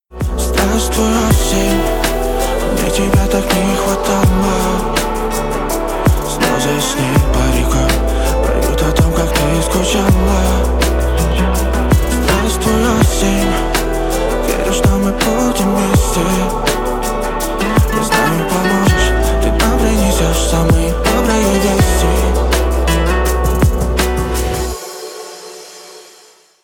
Нарезка припева на вызов